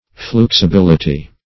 Search Result for " fluxibility" : The Collaborative International Dictionary of English v.0.48: Fluxibility \Flux`i*bil"i*ty\, n. [Cf. LL. fluxibilitas fluidity.] The quality of being fluxible.